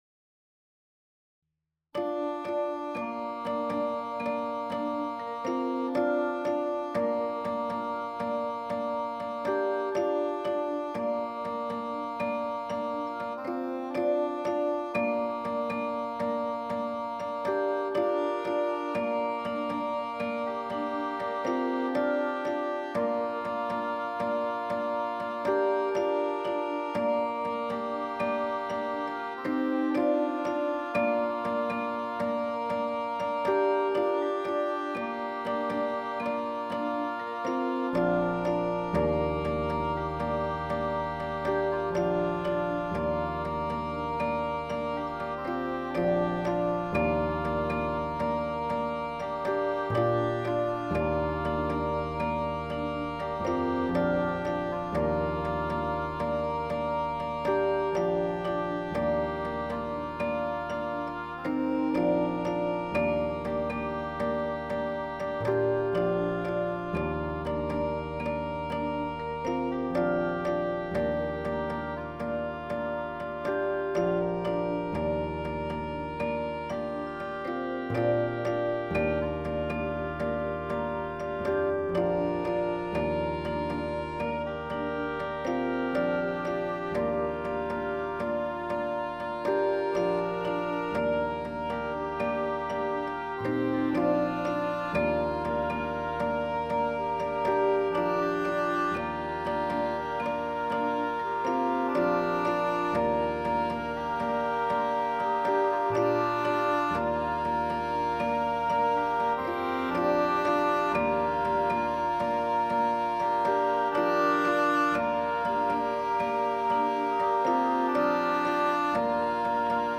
in ein orchestrales Gewand